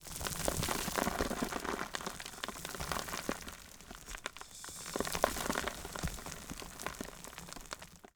sfx_坍塌.wav